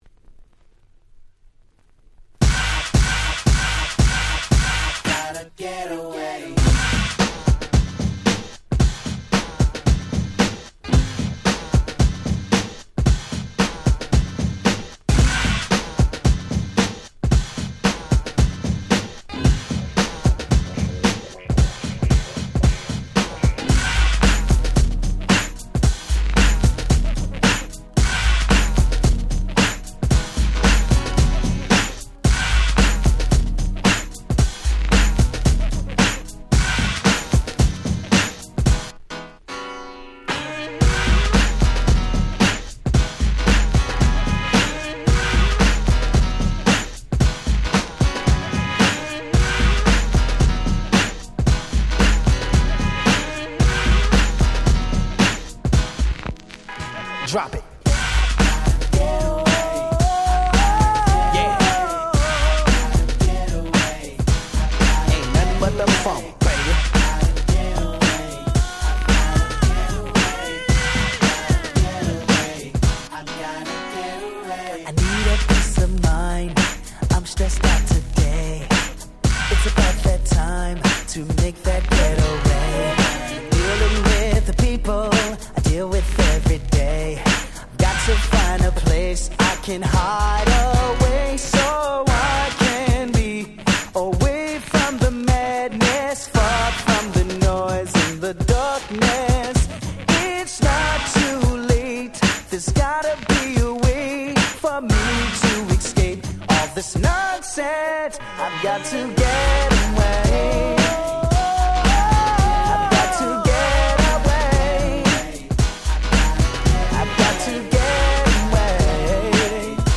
New Jack Swing Classic !!
ハネまくり！